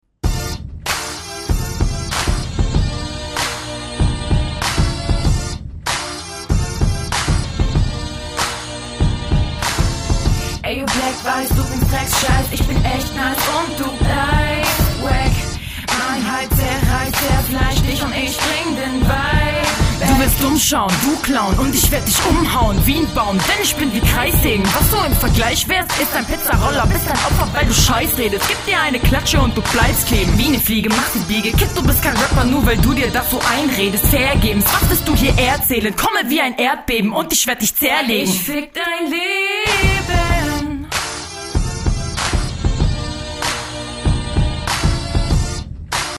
Der Gesang kommt jedenfalls nice …
Gesang ist nice, flow kommt auch sehr cool, textlich könnten paar mehr Punches drin sein …
finde den beat etwas anstrengend ,krazt voll im ohr... reime naja,standart,quali eher mittelmäßig aber was …
Nette Gesangseinlagen, mit etwas besserem Text könnte das echt was werden.